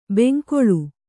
♪ benkoḷu